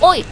Worms speechbanks
ooff3.wav